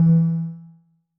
Techmino / media / effect / chiptune / ren_4.ogg
重做连击音效